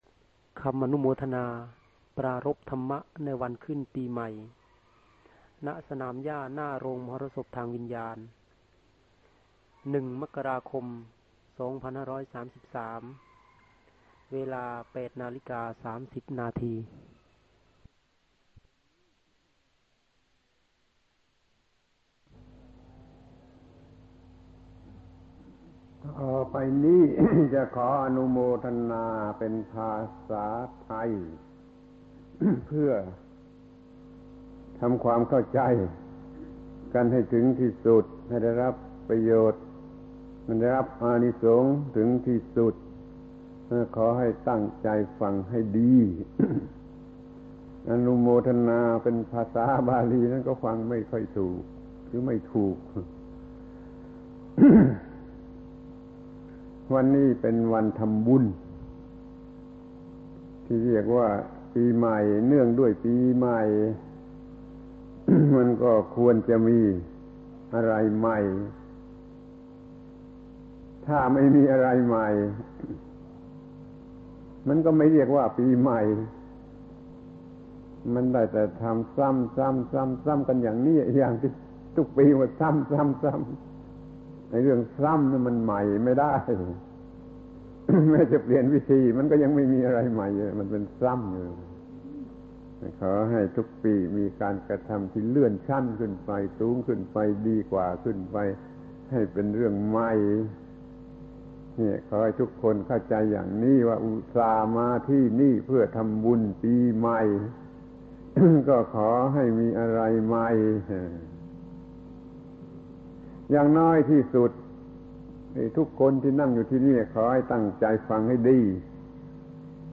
พระธรรมโกศาจารย์ (พุทธทาสภิกขุ) - อนุโมทนาในพิธีตักบาตรวันขึ้นปีใหม่ ปี 2533 พิธีตักบาตรและคำปราศรัยในวันขึ้นปีใหม่